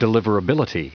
Prononciation du mot deliverability en anglais (fichier audio)
Prononciation du mot : deliverability
deliverability.wav